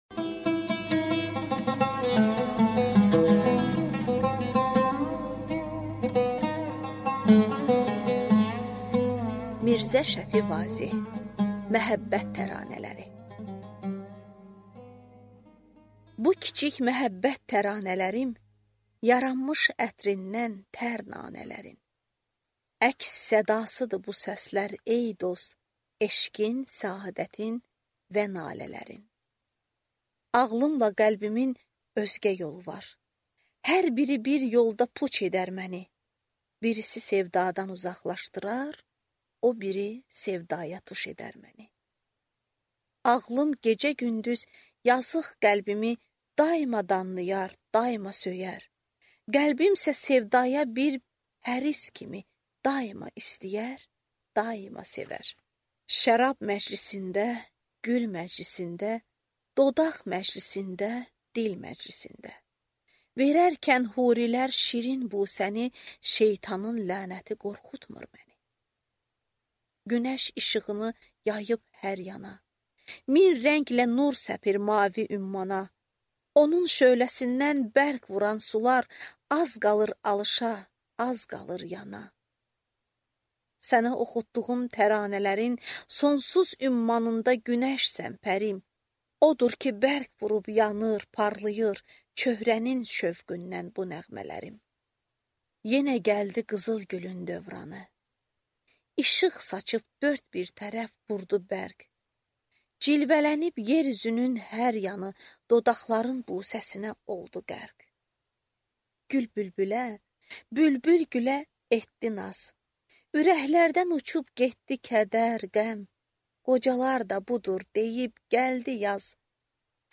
Аудиокнига Məhəbbət təranələri | Библиотека аудиокниг